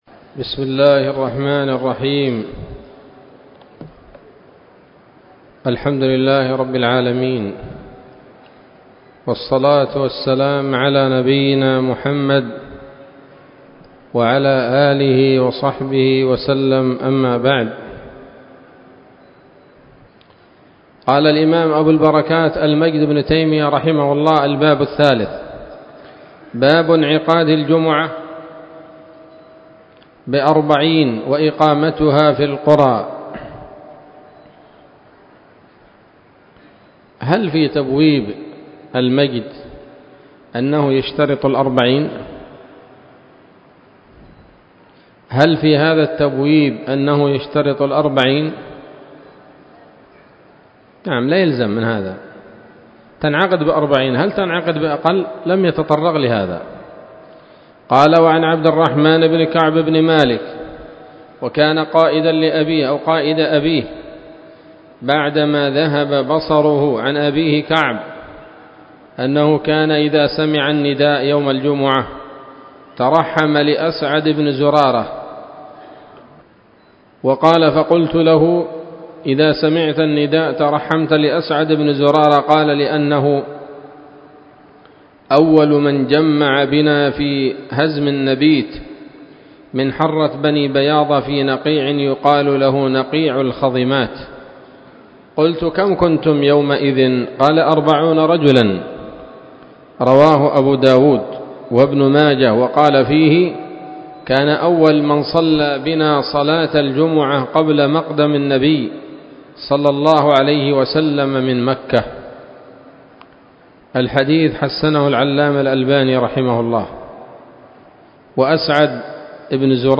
الدرس السادس من ‌‌‌‌أَبْوَاب الجمعة من نيل الأوطار